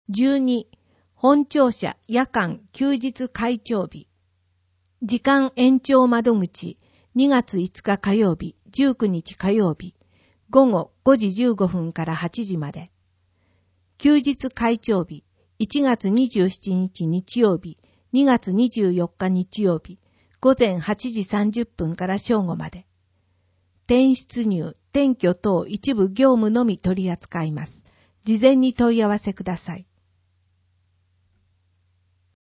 広報あづみの朗読版285号（平成31年1月24日発行)
この録音図書は、安曇野市中央図書館が制作しています。